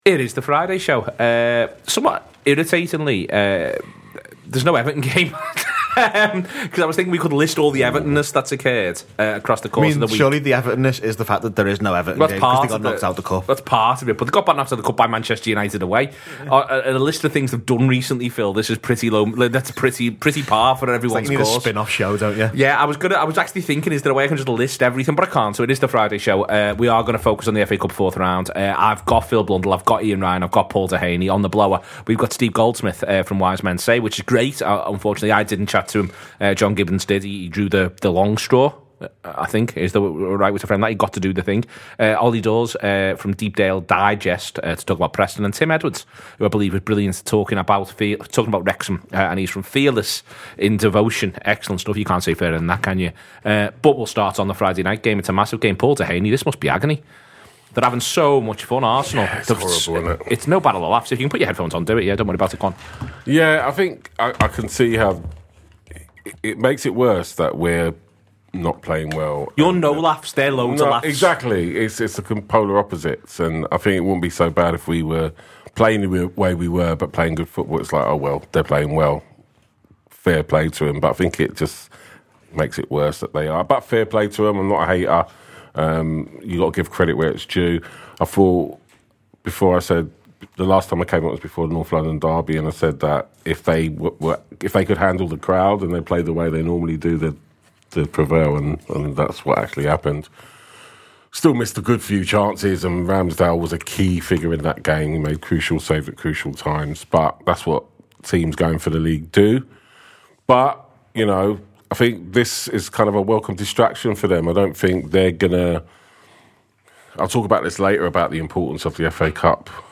Fans preview the 2023 FA Cup fourth round, as Sunderland, Preston North End and Wrexham all eye up further giant killings.